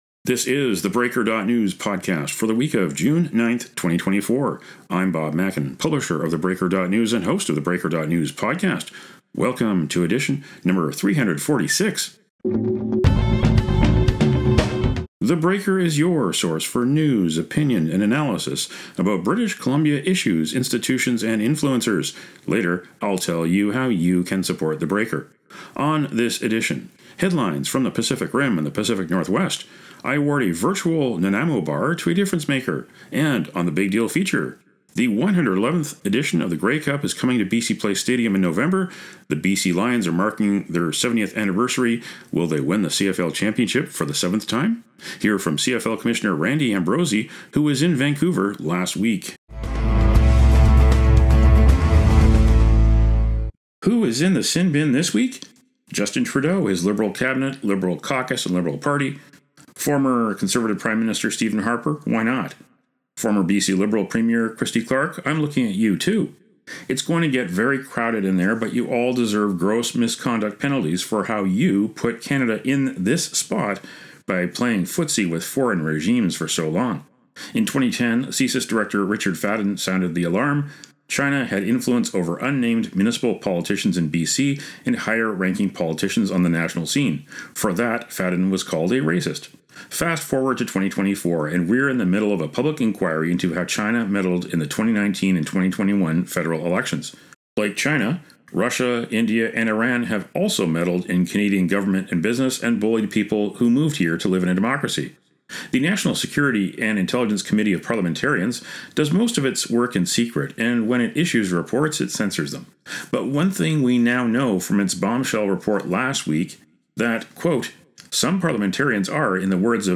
He was in Vancouver on June 3 for a wide-ranging, question and answer session with reporters